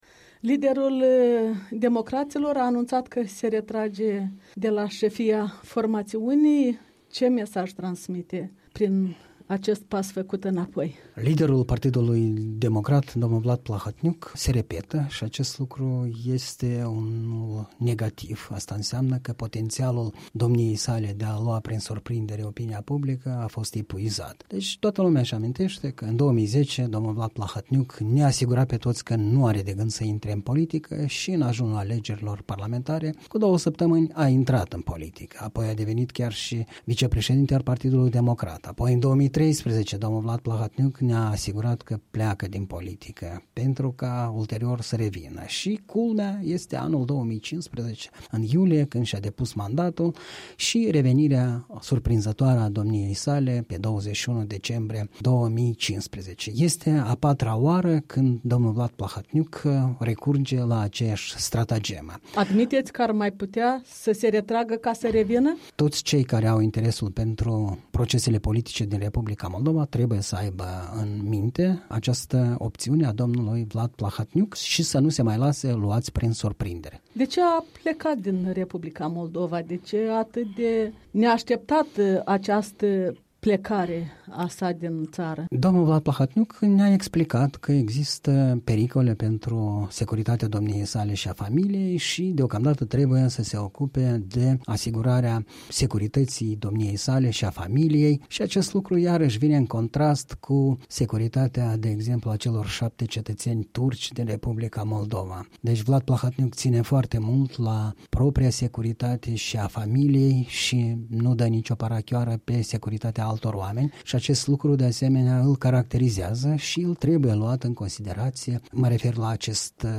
Comentariu săptămânal, în dialog la Europa Liberă.